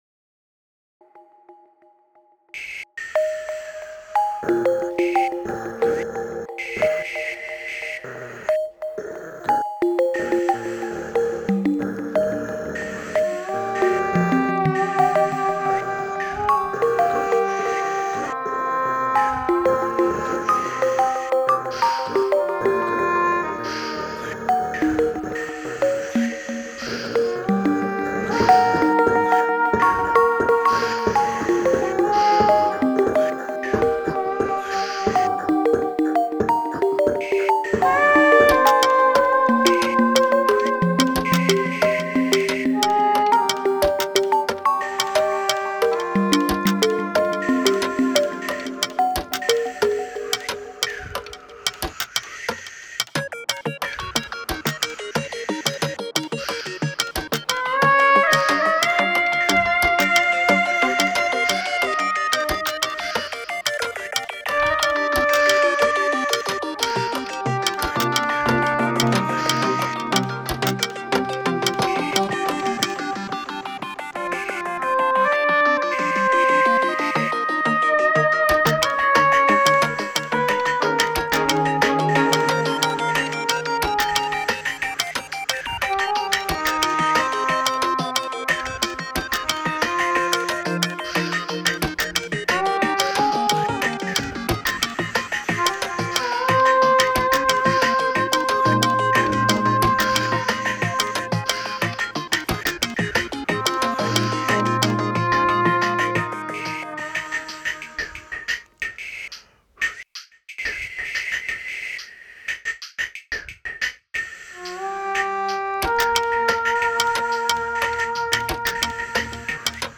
Using XLN Life I made attempts at growling and wind sounds, lol. Also some bamboo sticks. Dune synth. Audio Modeling english horn played using NuEVI (wind controller)